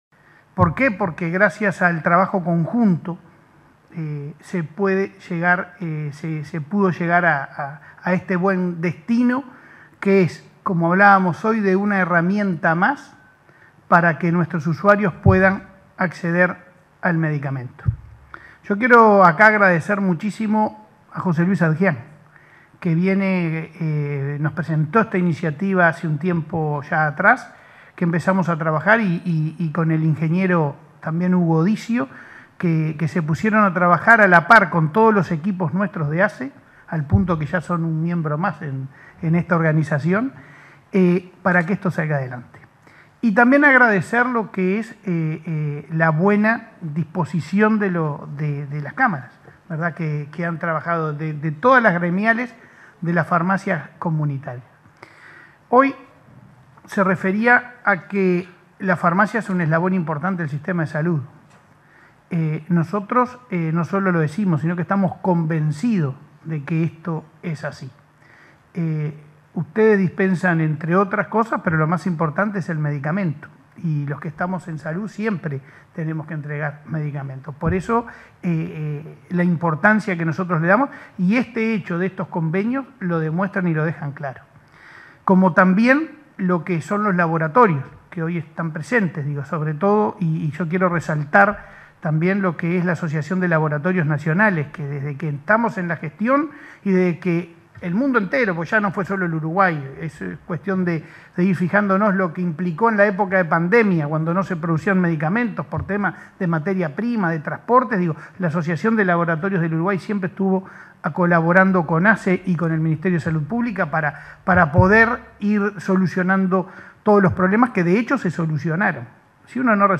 Palabras del presidente de ASSE, Leonardo Cipriani